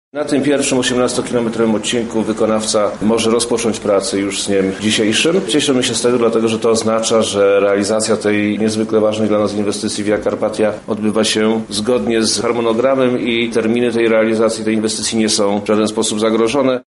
• Mówi Przemysław Czarnek